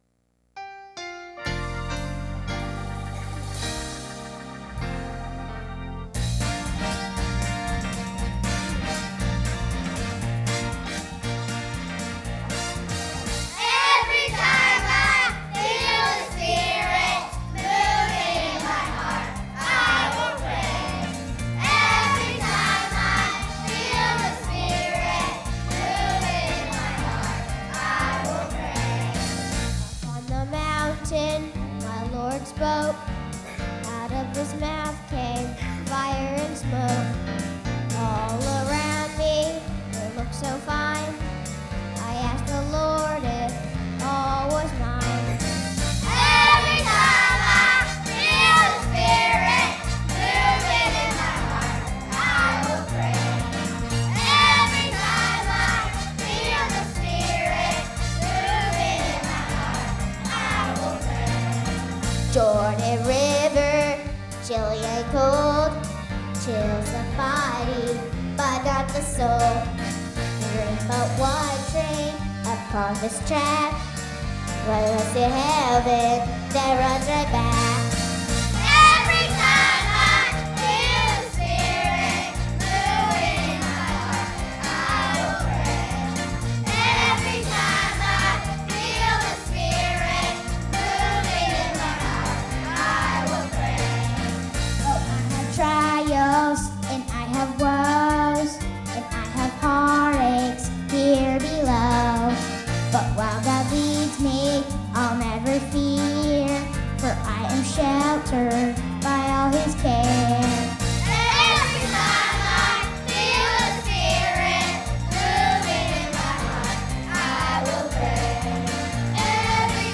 Sunday Morning Music
God's Kids Choir